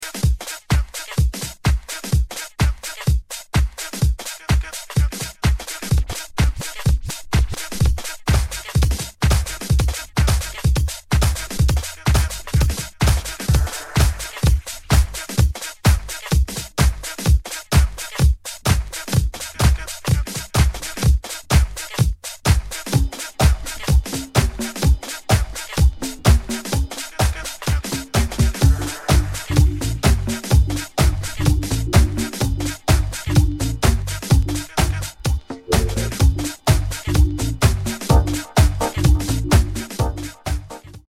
Beatmatching on Vinyl Using Pitch sound effects free download
In this video I’m demonstrating how to beatmatch two records using the pitch riding technique. This means making micro adjustments to the pitch fader while the track is playing - a method that’s all about timing and control. 💡 It’s especially useful when nudging or platter control feels too aggressive, or when you want smoother, less noticeable corrections mid-mix.